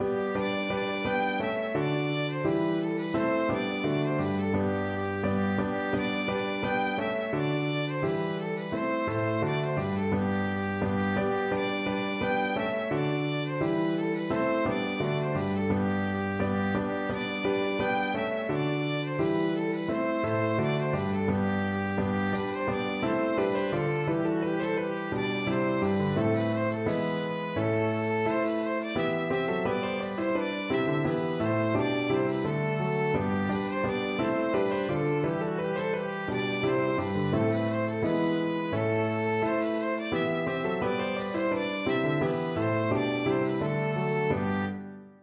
2/2 (View more 2/2 Music)
= c. 86
Arrangement for Violin and Piano
Classical (View more Classical Violin Music)